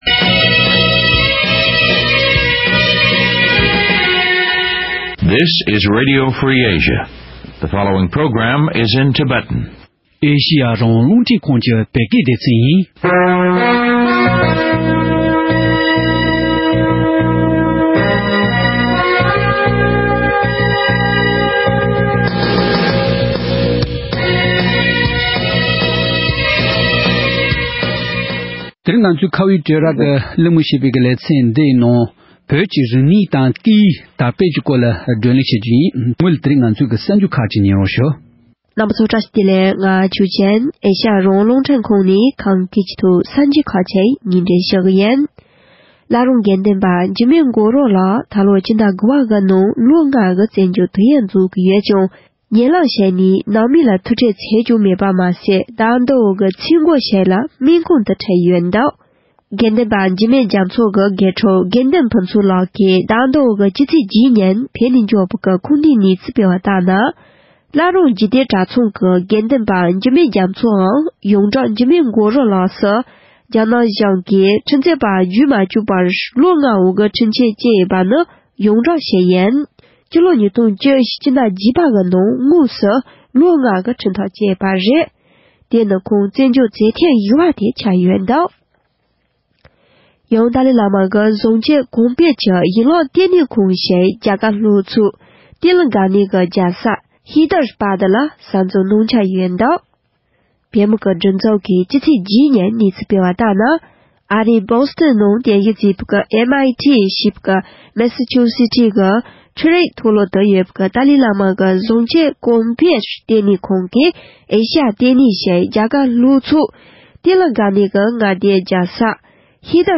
བཙན་འབྱོལ་དུ་བོད་ཀྱི་སྐད་ཡིག་དང་རིག་གནས་དར་སྤེལ་གྱི་སྐོར་ལ་གླིང་མོལ་ཞུས་པ།